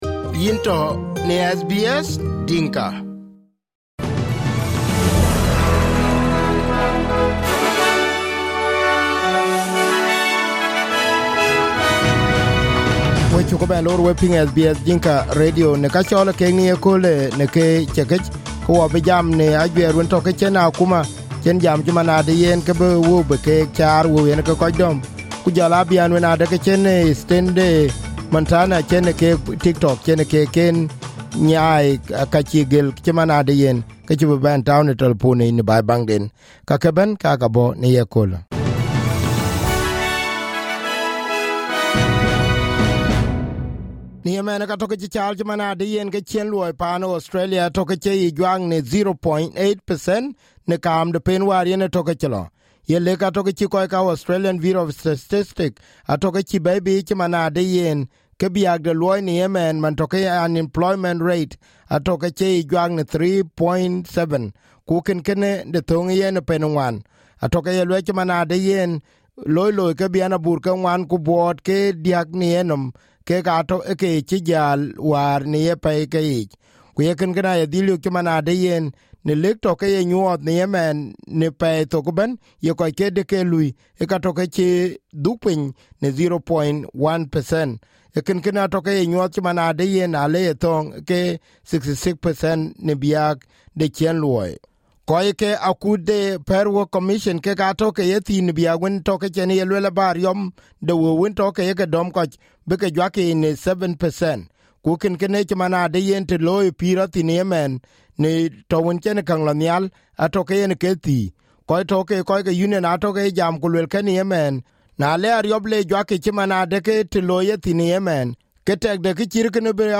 SBS Dinka News Bulletin 18/05/2023